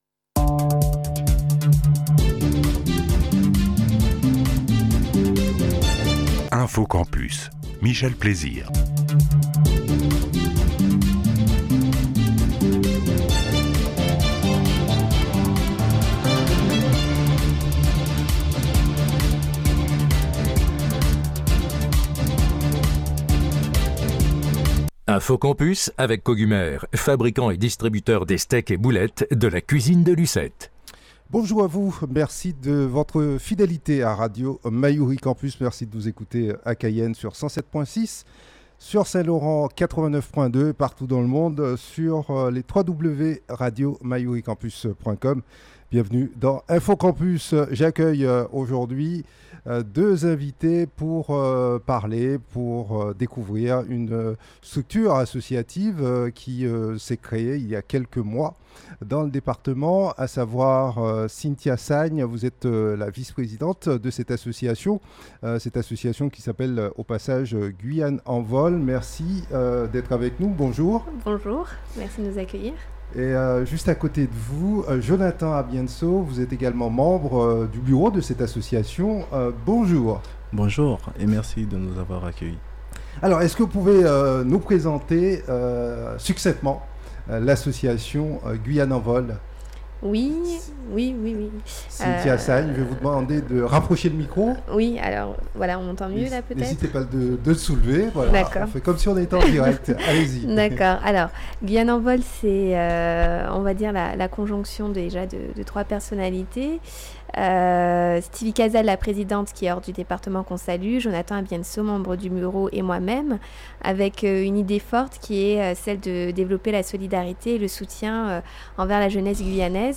Dans le cadre de l'émission quotidienne Info Campus à 8h30 sur Radio Mayouri Campus.